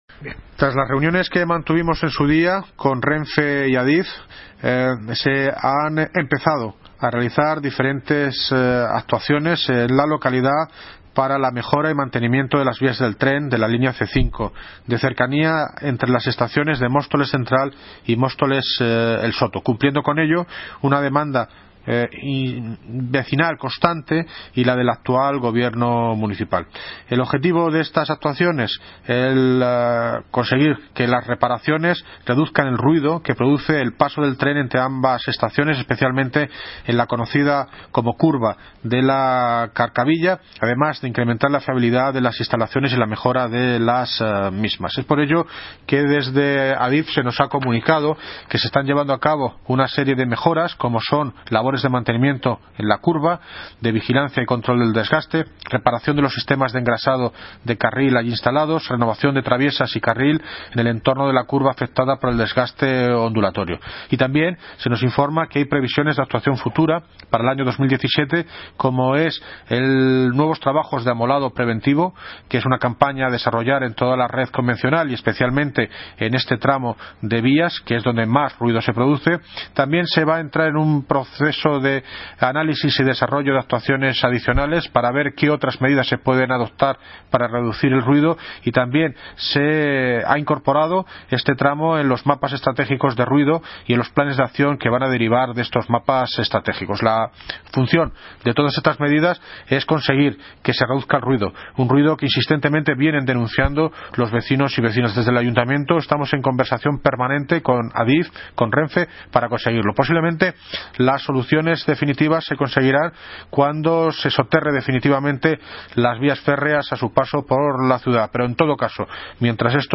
Audio - David Lucas (Alcalde de Móstoles) Sobre Obras renfe